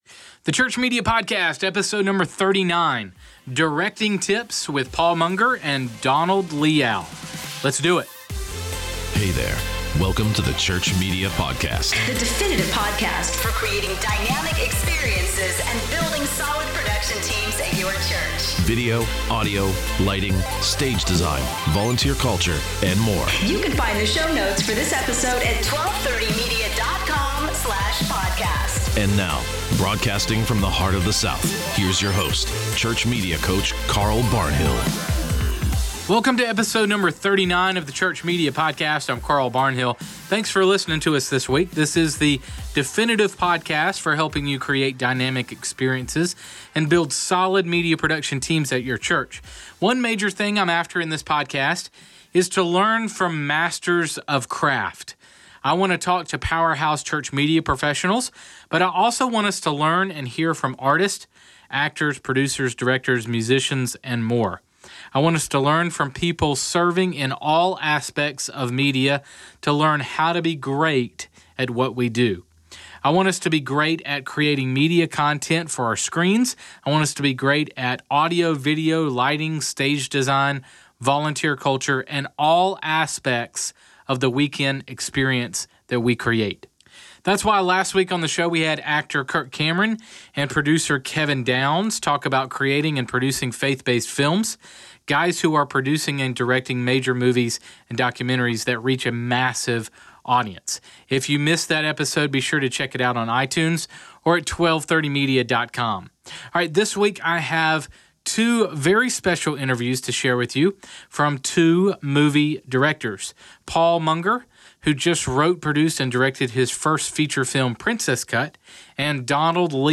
This week I have two very special interviews to share w